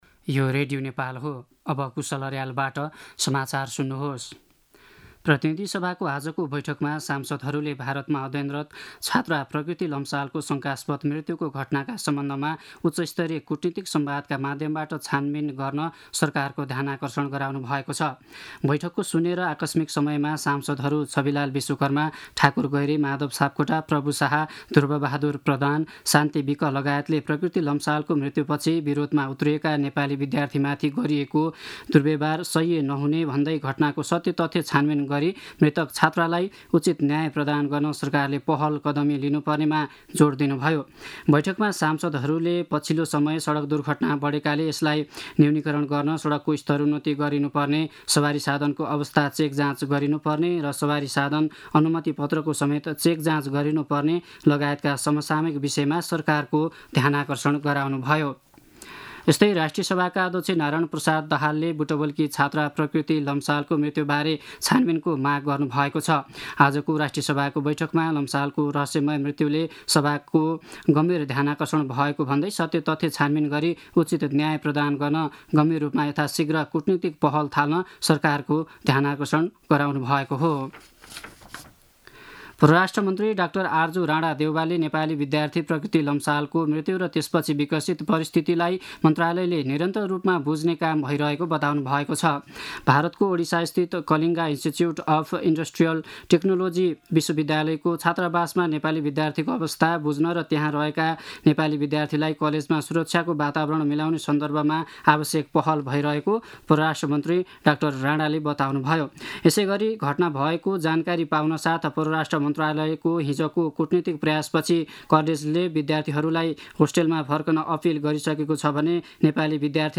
दिउँसो ४ बजेको नेपाली समाचार : ७ फागुन , २०८१
4-pm-news-5.mp3